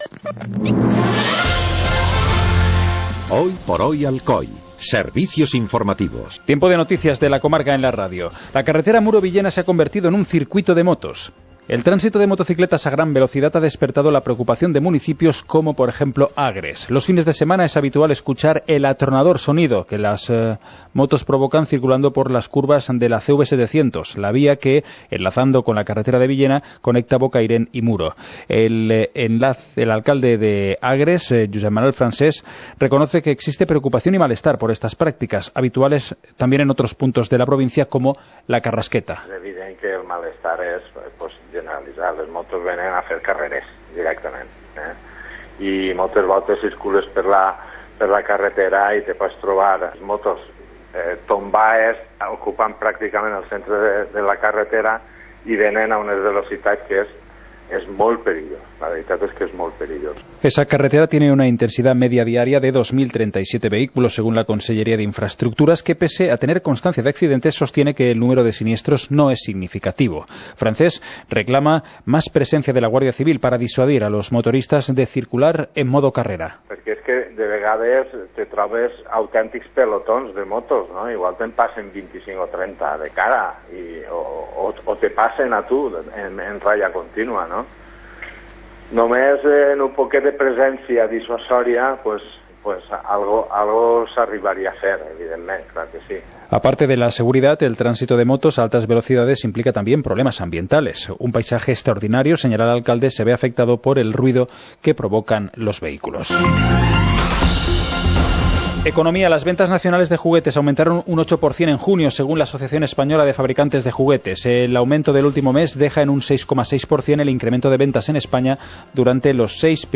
Informativo comarcal - viernes, 14 de julio de 2017